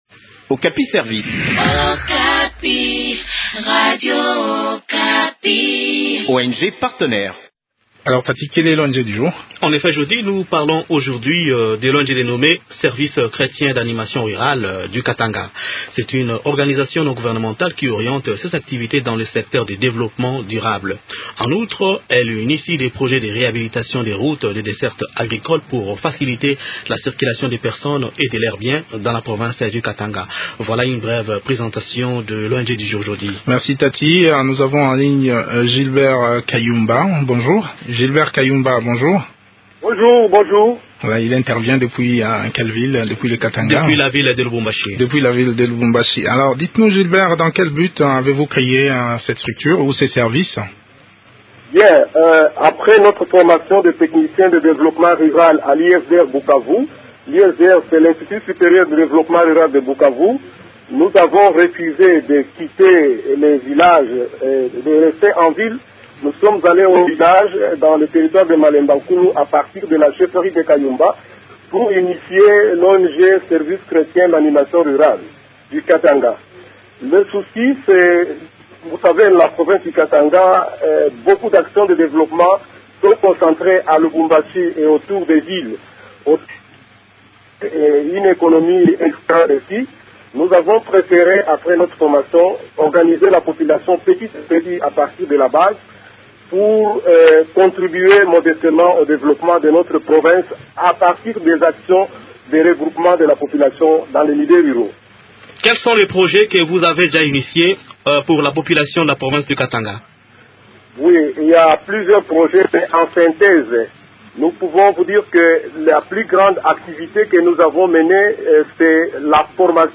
Le service chrétien d’animation rurale du Katanga est une organisation non gouvernementale. Créée en 1988, cette ONG est opérationnelle au Katanga notamment dans le secteur de réhabilitation des routes de desserte agricole, des projets de développement durable dans l’agriculture, pêche et élevage. Découvrons les activités de cette ONG dans cet entretient